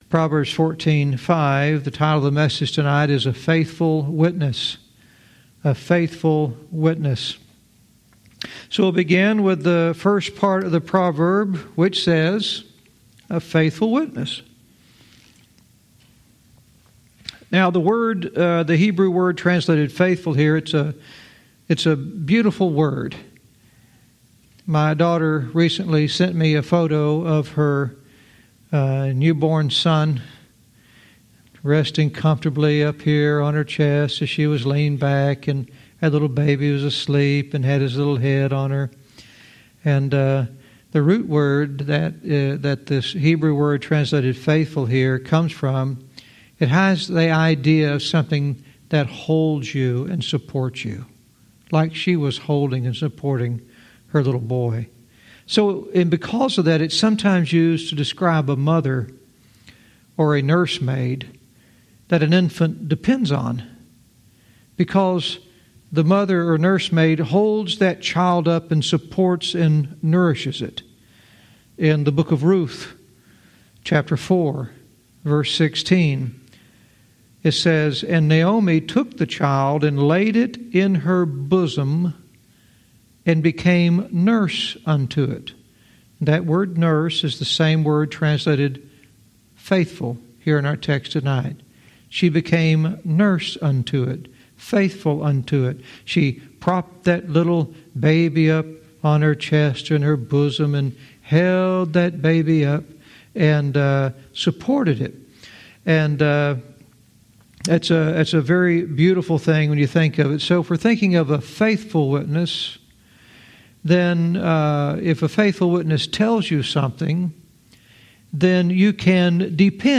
Verse by verse teaching - Proverbs 14:5 "A Faithful Witness"